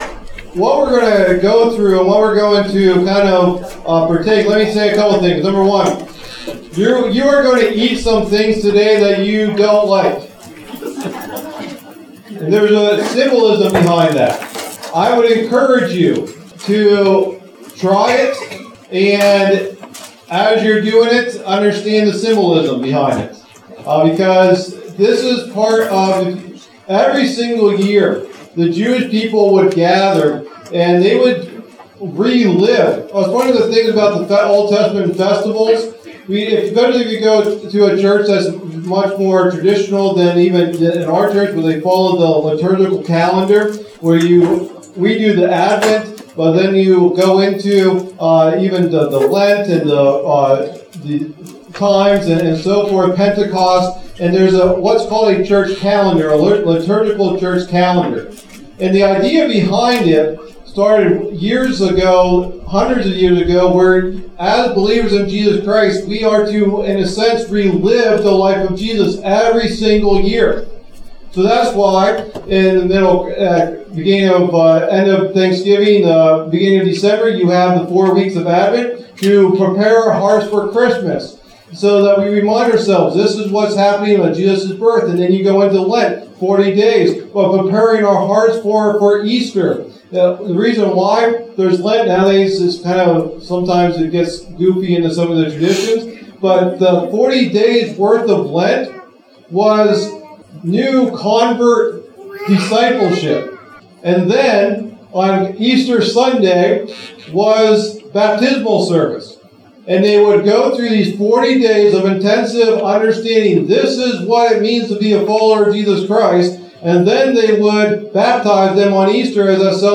Sunday Morning Teachings | Bedford Alliance Church